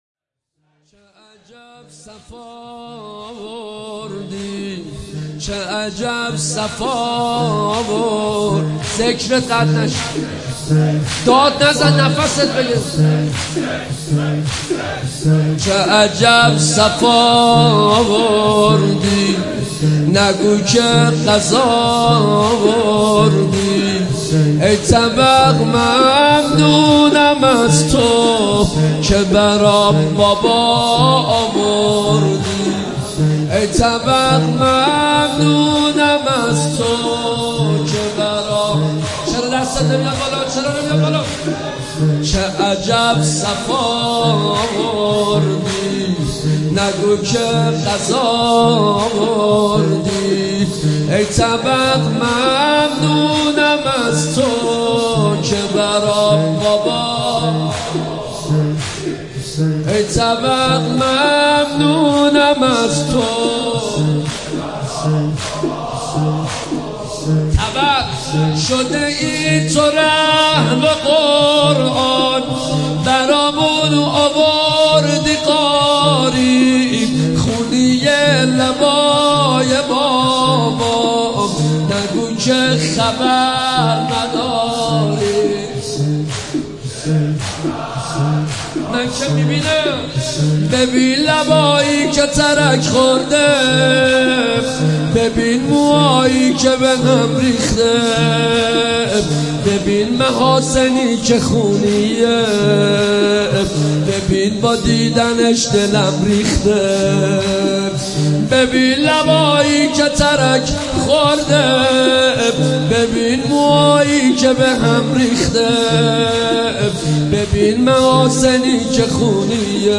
شب سوم محرم
مداحی
نوحه